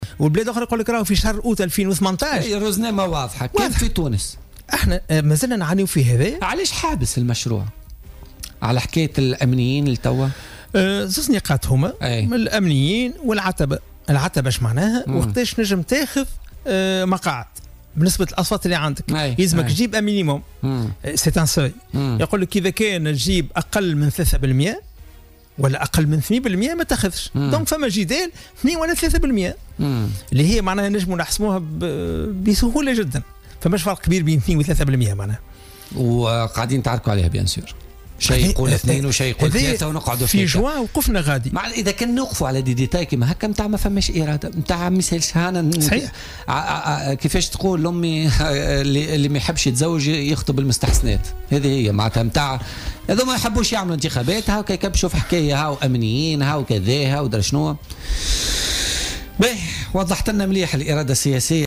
وأوضح ضيف "بوليتيكا" أن النواب اختلفوا حول "العتبة" وهي نسبة الأصوات المتحصل عليها والتي تخوّل الحصول على مقاعد انتخابية.